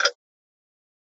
Clock.ogg